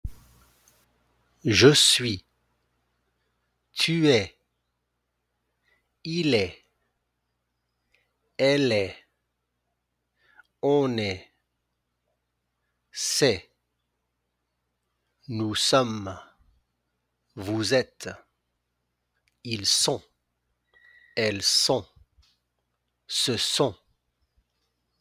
• the final consonants of the verb forms are not pronounced. I.e. es, sommes, sont
• the liaison between the subject pronoun and the verb. I.e. ”Il∼est”, ”On∼est” and ”vous∼êtes”.
1.18-Conjugaison-du-verbe-Etre.mp3